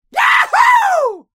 Cheer3.wav